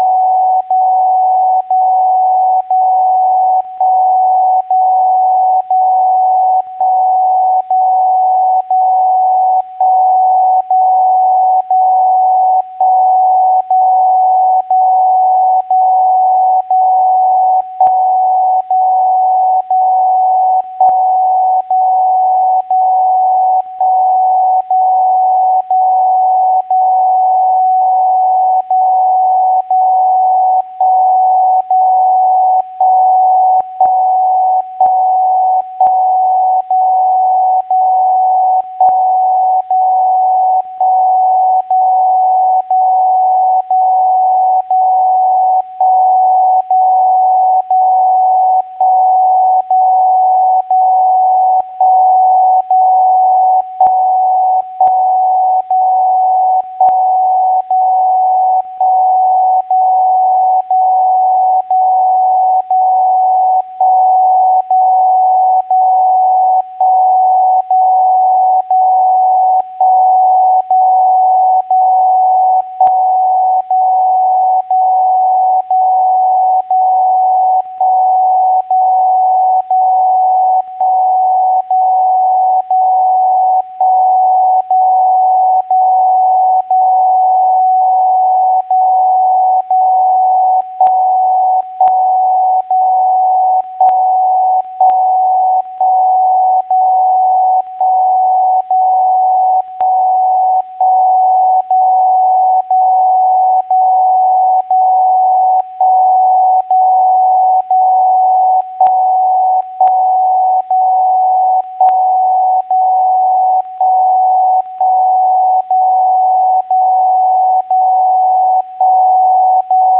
DCF77 - Signalverarbeitung und Dekodierung von ausgetasteter 750 Hz-AM
DCF77_Test_File_WebSDR_heute.wav